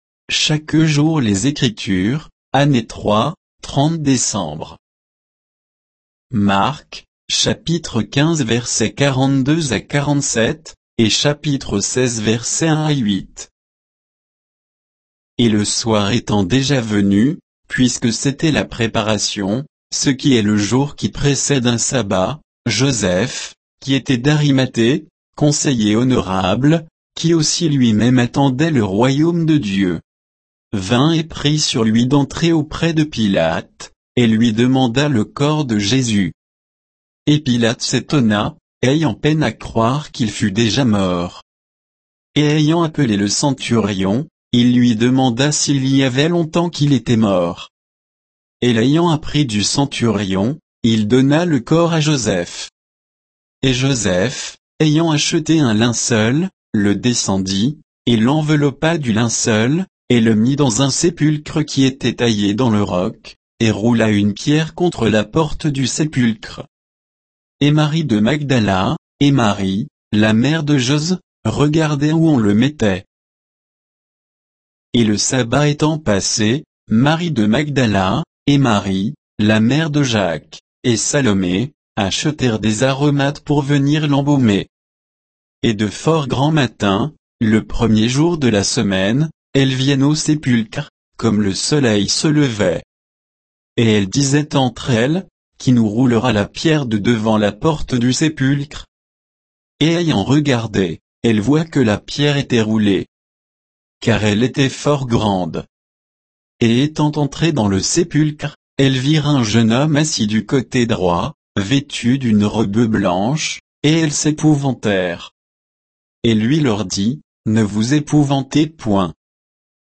Méditation quoditienne de Chaque jour les Écritures sur Marc 15, 42 à 16, 8